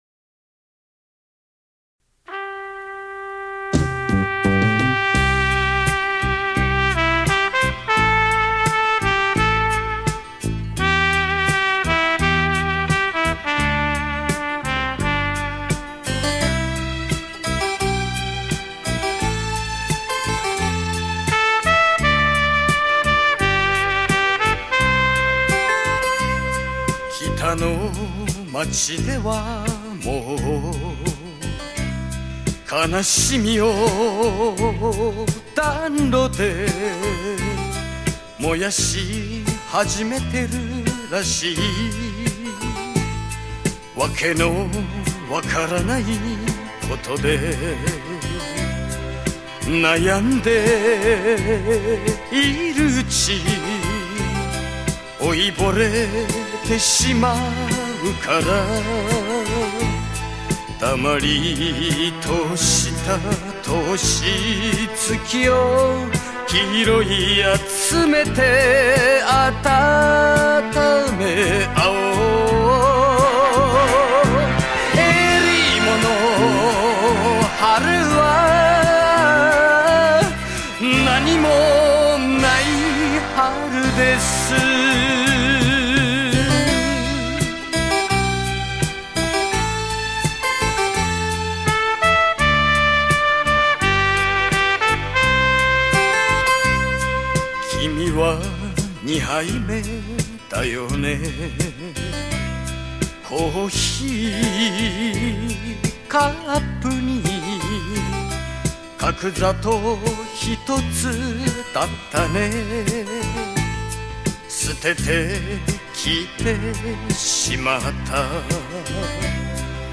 收录日本演歌精选 曲曲动听 朗朗上口